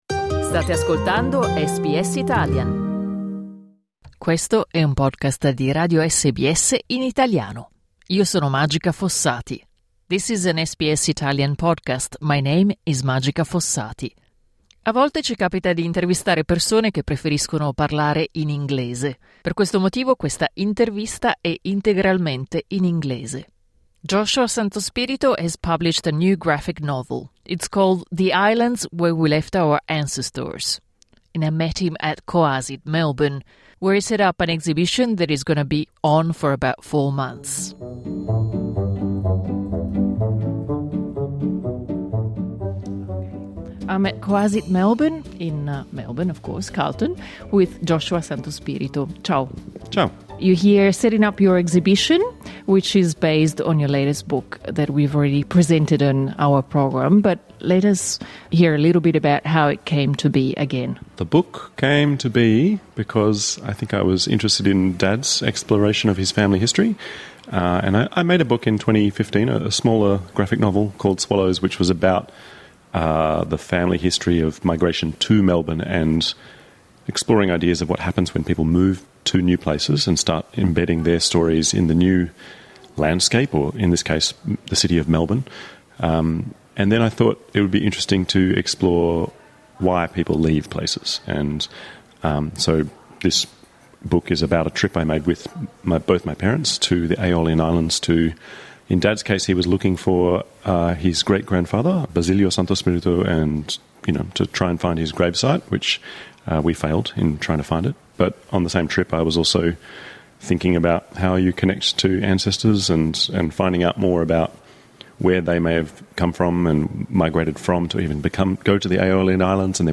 interview in English